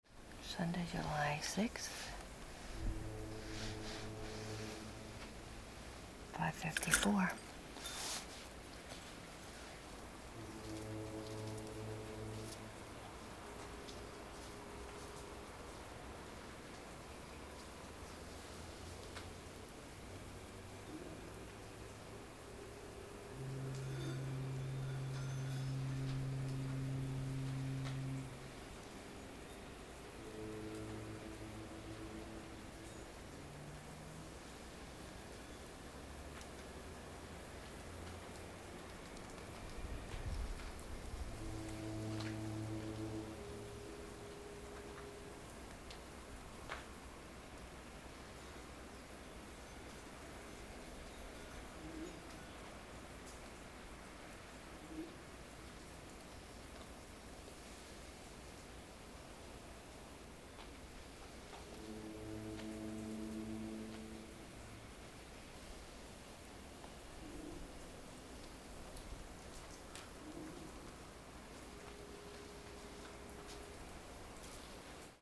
And then the gentle and insistent tones of the foghorns. Low, soft-edged and several different notes form a never repeating, random melody.
Peasoup with music.